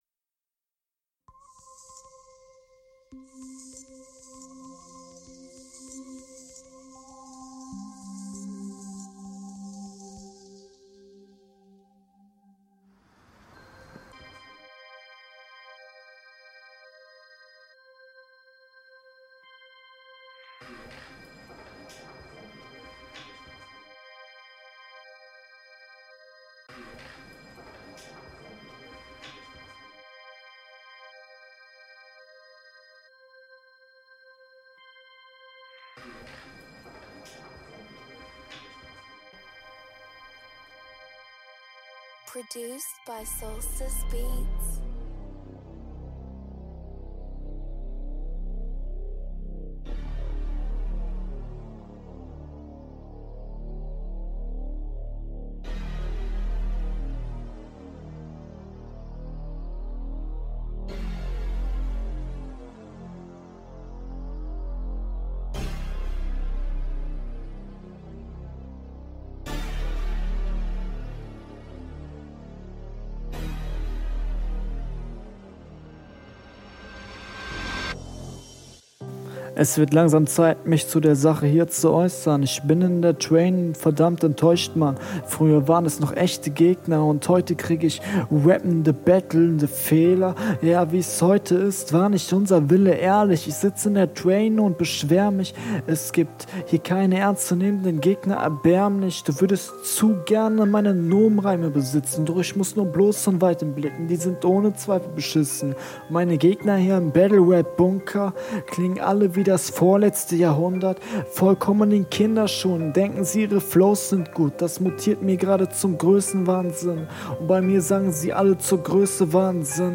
Bitte nimm keine 1min20sek Intros für deine Beats.